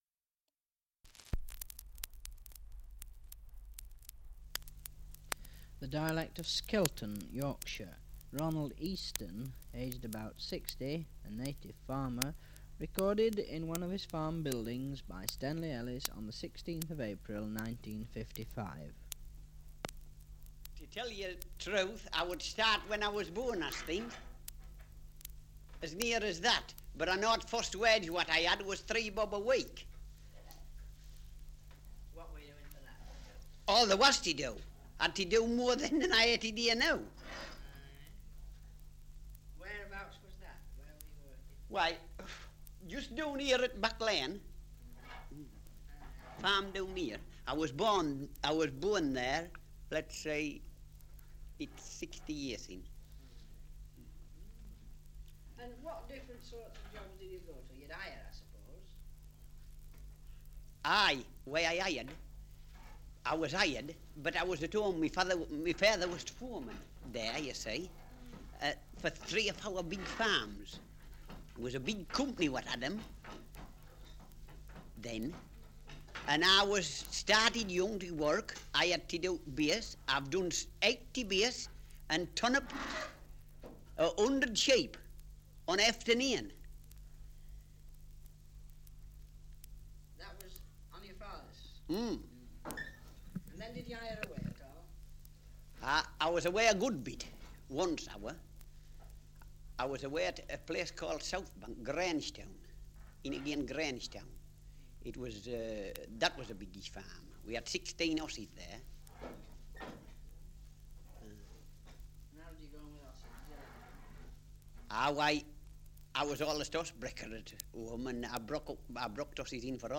Survey of English Dialects recording in Skelton, Yorkshire
78 r.p.m., cellulose nitrate on aluminium